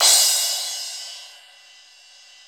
Index of /90_sSampleCDs/Sound & Vision - Gigapack I CD 1 (Roland)/CYM_K-CRASH st/CYM_K-Crash st 2
CYM CRA3101R.wav